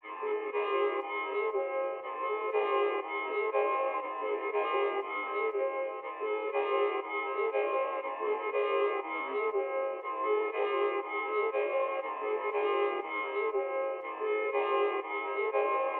EN - Hard (120 BPM).wav